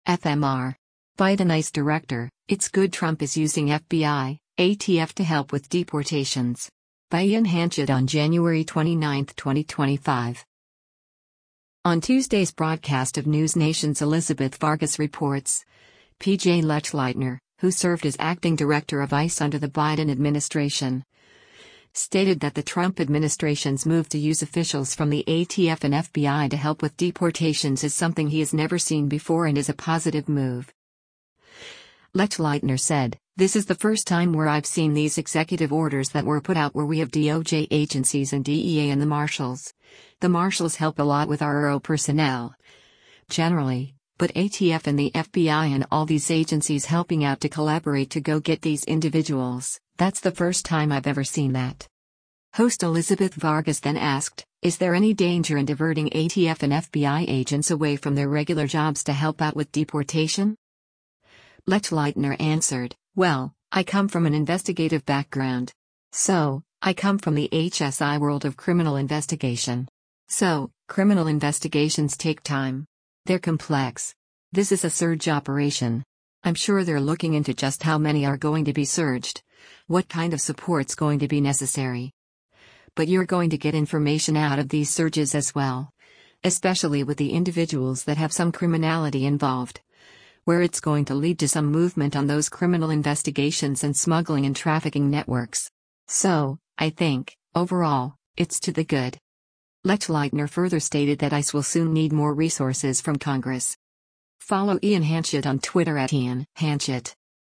On Tuesday’s broadcast of NewsNation’s “Elizabeth Vargas Reports,” P.J. Lechleitner, who served as acting Director of ICE under the Biden administration, stated that the Trump administration’s move to use officials from the ATF and FBI to help with deportations is something he’s never seen before and is a positive move.